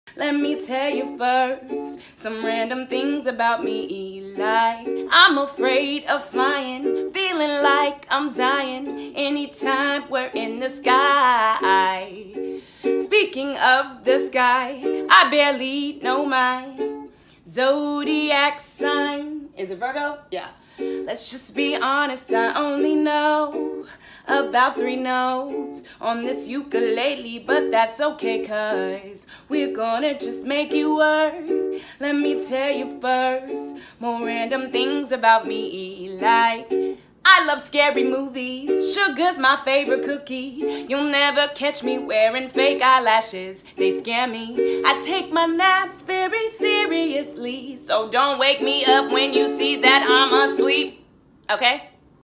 i can only play 3 notes on the ukulele